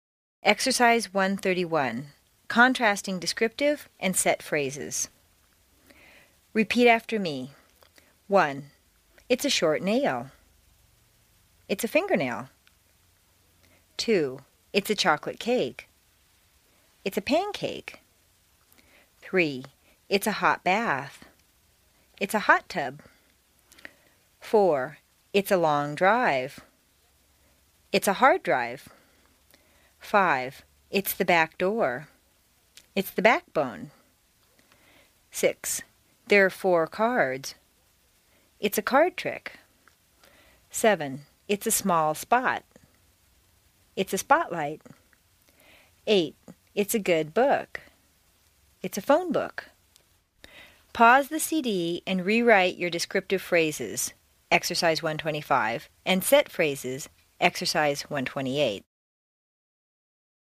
在线英语听力室美式英语正音训练第25期:Exercise 1-31 Contrasting Descriptive and Set Phrases的听力文件下载,详细解析美式语音语调，讲解美式发音的阶梯性语调训练方法，全方位了解美式发音的技巧与方法，练就一口纯正的美式发音！